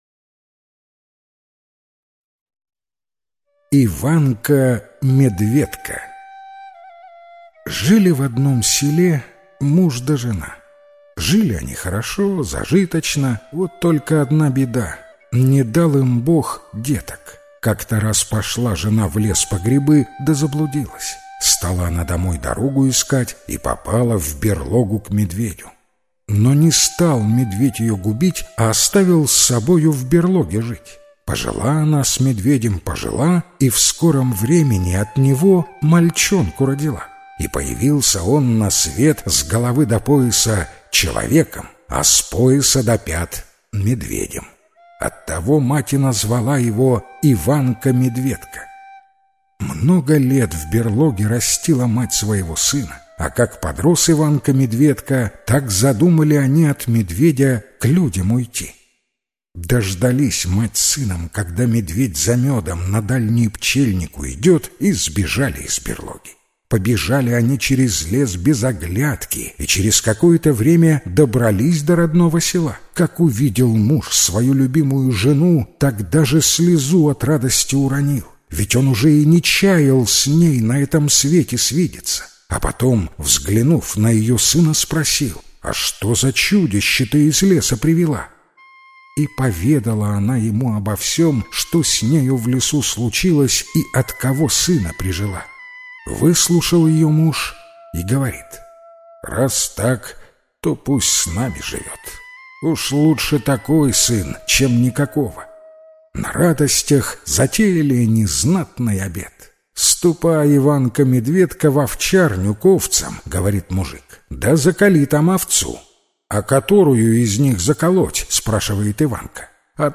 Иванко-медведко - белорусская аудиосказка - слушать онлайн